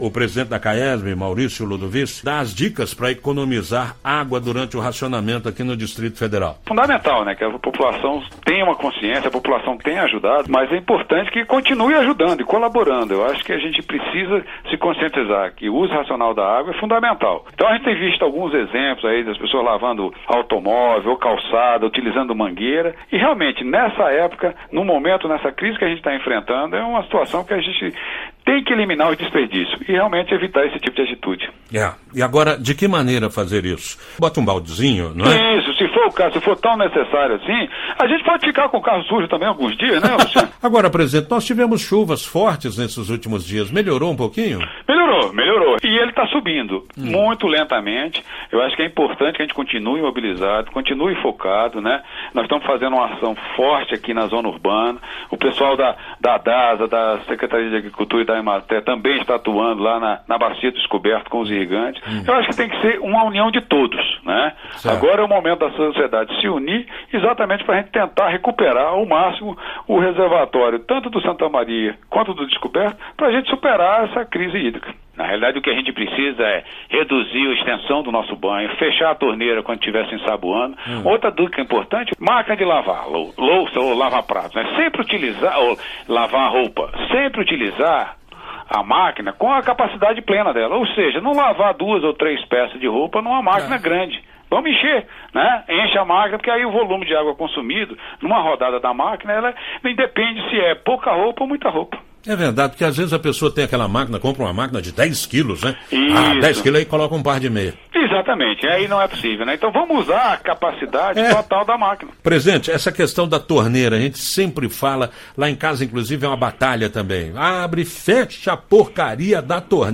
Entrevista: Conheça as dicas de economia de água para o racionamento no DF